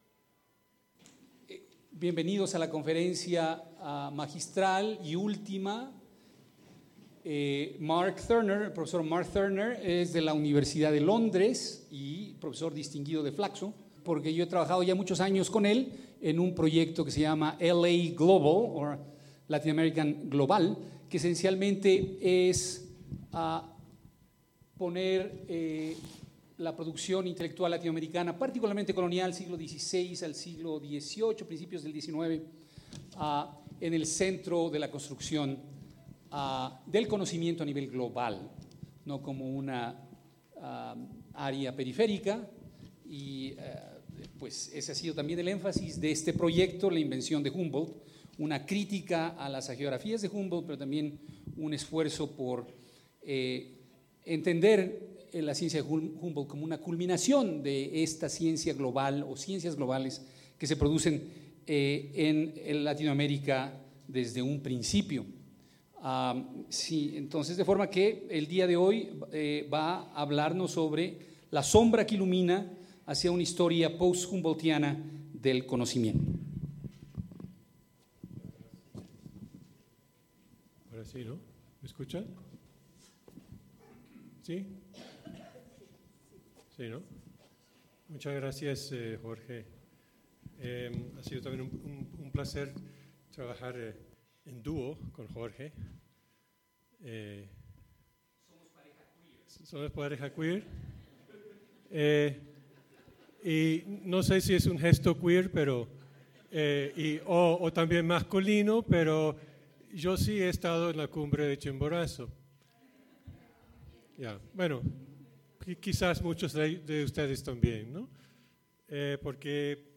Tipo de Material: Conferencias magistrales
Simposio La invención de Humboldt.